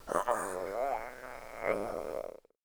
DayZ-Epoch/SQF/dayz_sfx/zombie/idle_32.ogg at f209148a32bde5ebcb1574a815d1c41f62396cdc